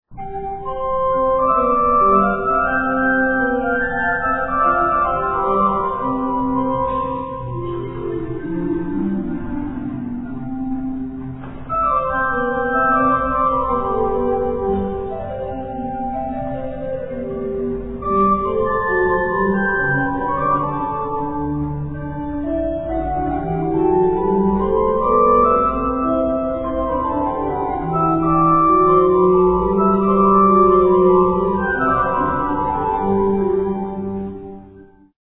Gregorian Chant